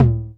D2 TOM-31.wav